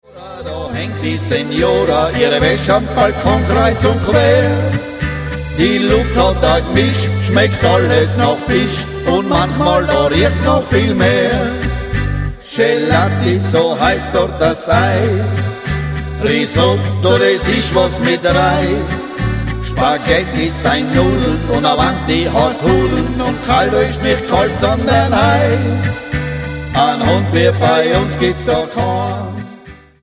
Genre: Volkstümliche Musik
Akkordeon, Gesang
Gitarre, Gesang
Kontrabass, Gesang